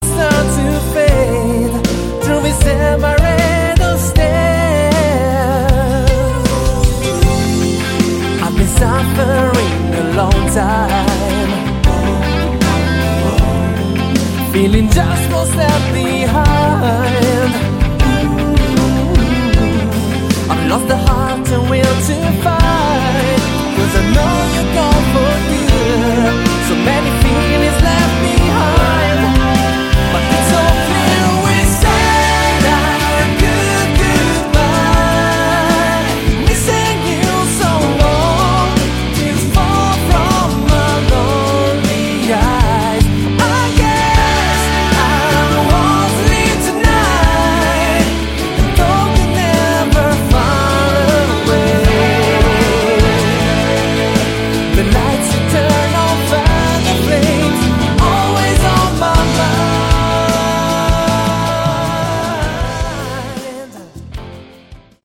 Category: AOR / Melodic Rock
guitar, vocals, keyboards
bass
drums